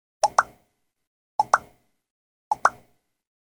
SFX – FACEBOOK 3